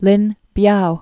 (lĭn byou) or Lin Piao (pyou, byou) 1907-1971.